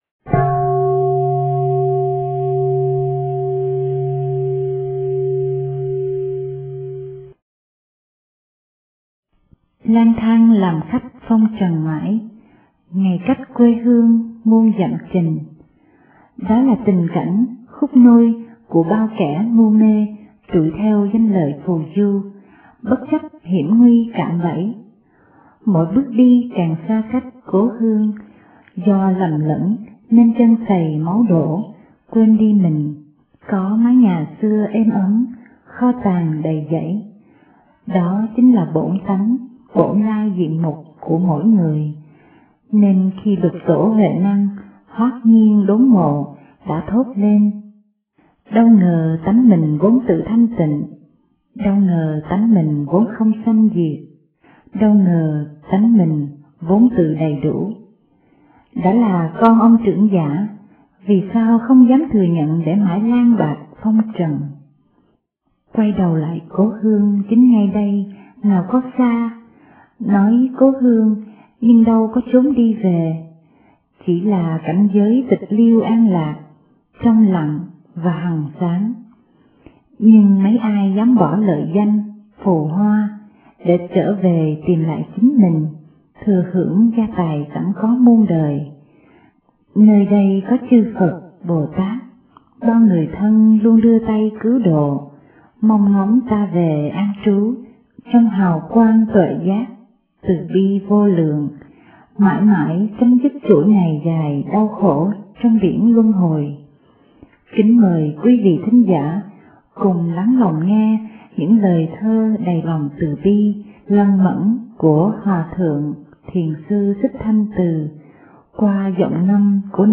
Bản ngâm Nghe Ngâm Thơ]